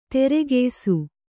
♪ teregeysu